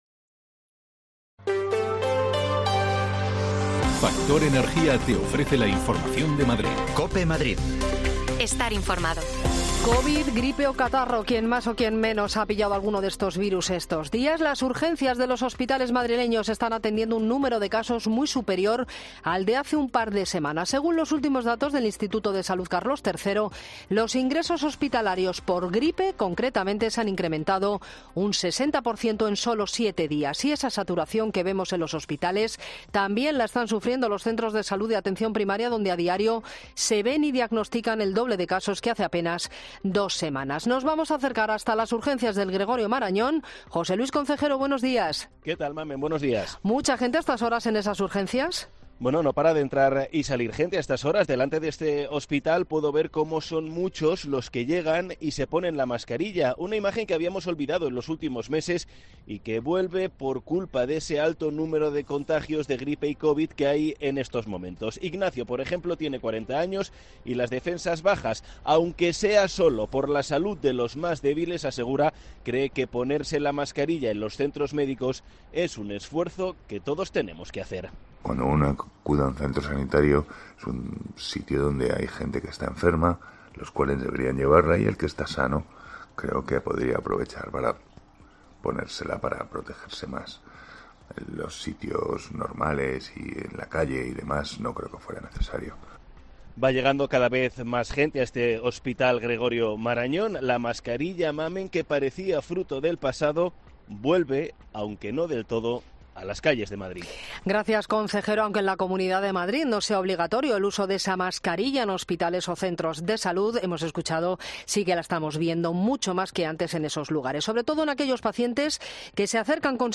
COPE se ha desplazado a las urgencias del hospital Gregorio Marañón de Madrid para comprobar si se está notando un repunte de los virus respiratorios.